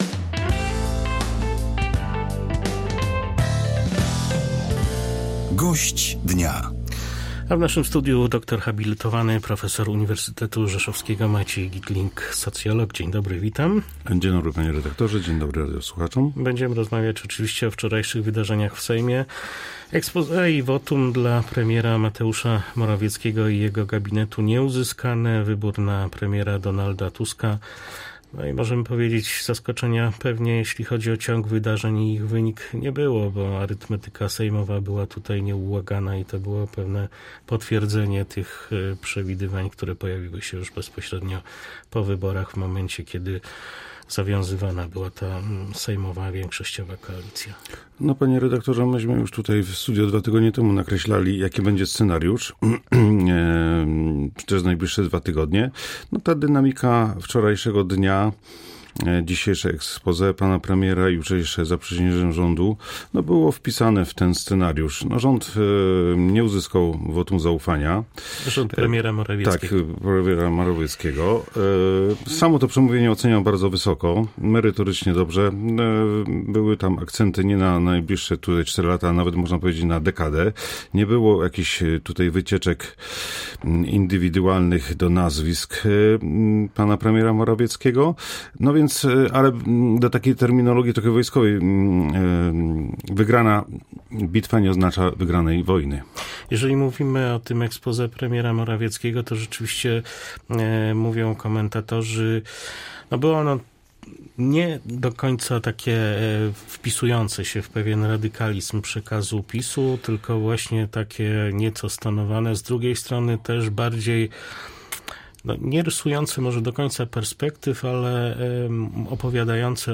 – uważa gość Polskiego Radia Rzeszów.